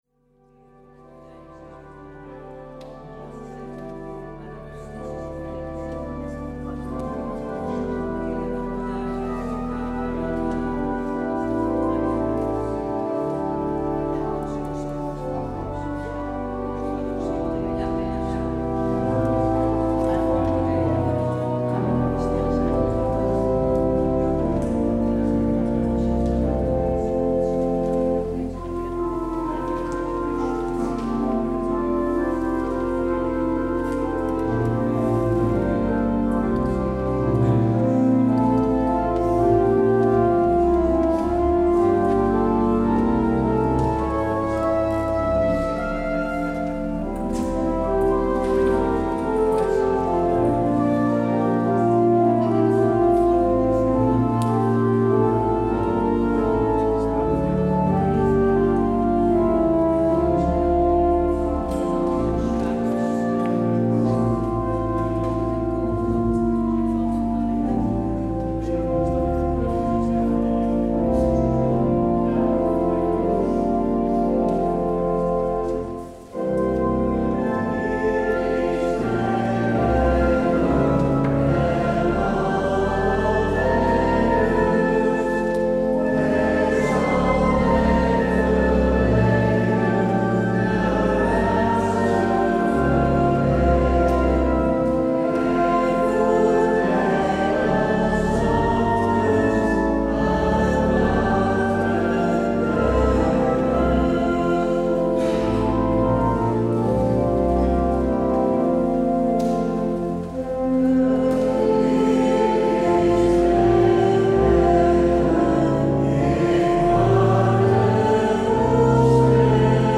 Luister deze kerkdienst hier terug
Het openingslied is: NLB 726: 1 en 6.
Het slotlied is: NLB 769: 1 en 6.